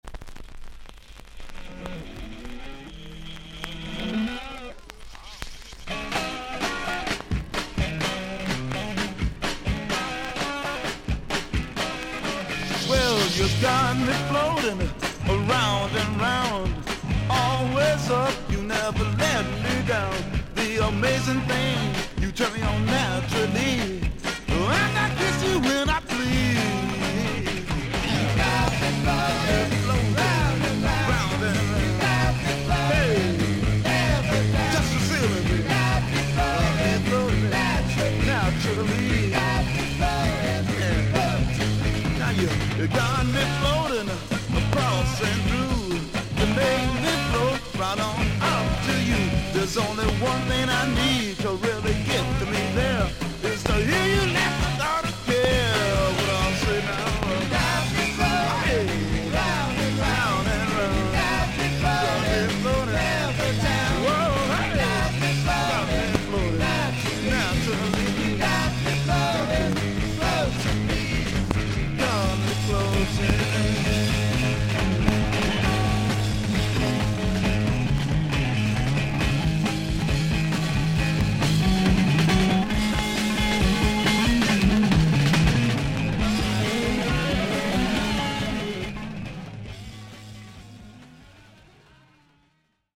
B1最初から中盤まで斜めに4.5cmのキズ、周回ノイズがあります。
ほかはVG+〜VG:盤面に長短のキズ、スリキズがあり、全体的にサーフィスノイズあり。所々に少々パチノイズの箇所あり。